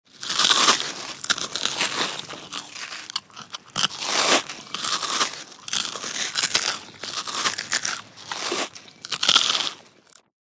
DryCerealBiteMunch PE678301
DINING - KITCHENS & EATING DRY CEREAL: INT: Bite into, slow dry munching.